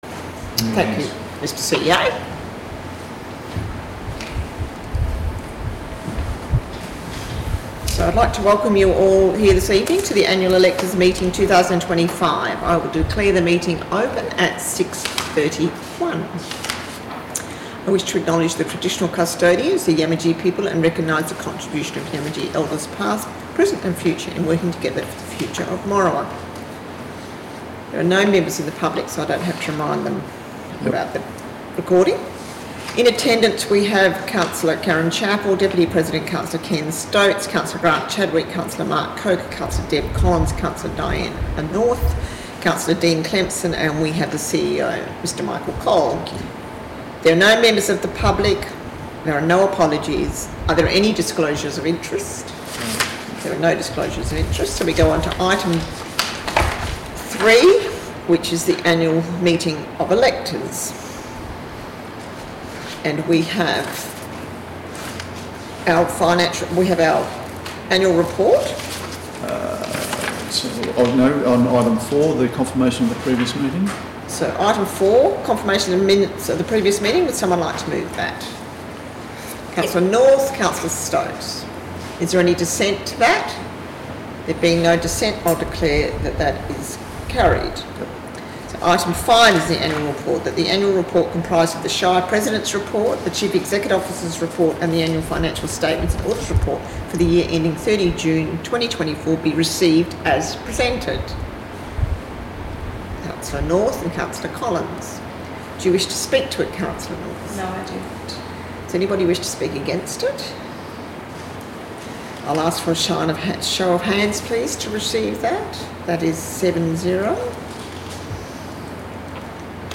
3-february-2025-annual-electors-meeting-minutes-recording